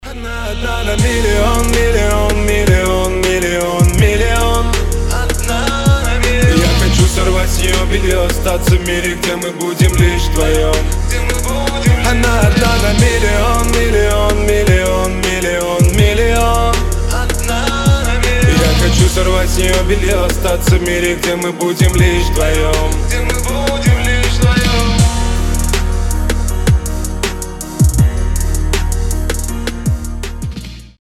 • Качество: 320, Stereo
басы